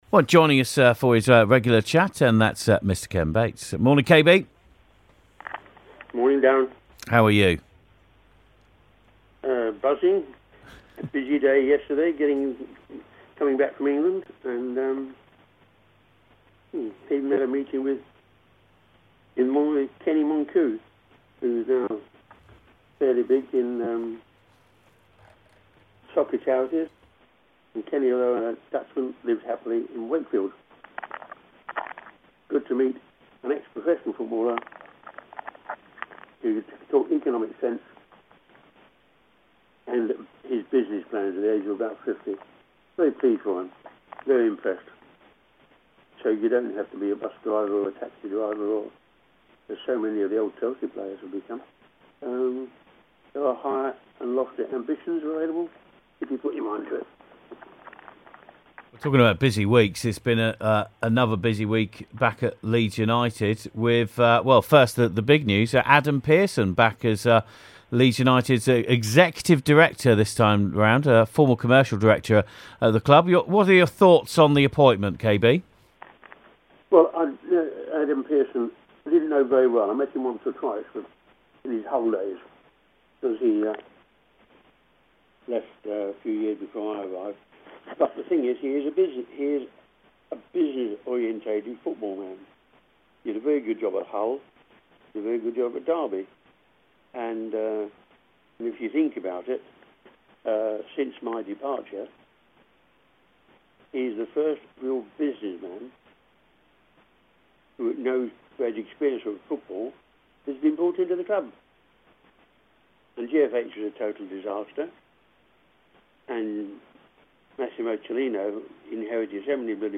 Ken Bates Interview 14/5